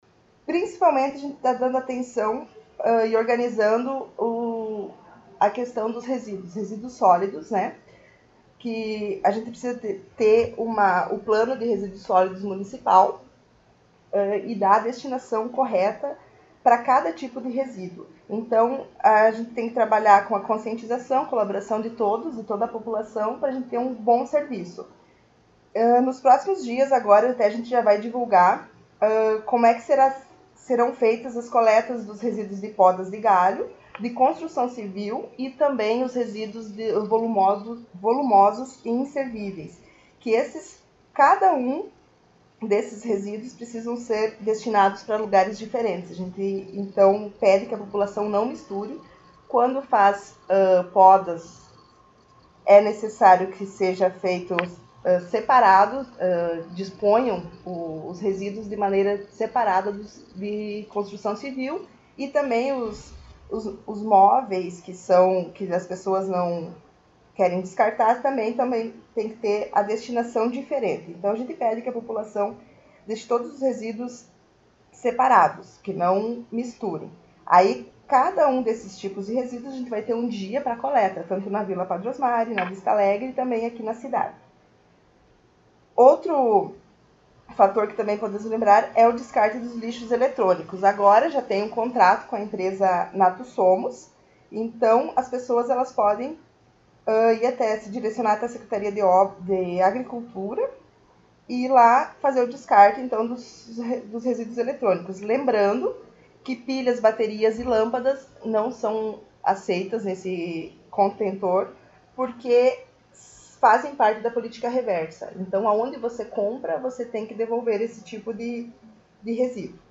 Secretária Municipal do Meio Ambiente concedeu entrevista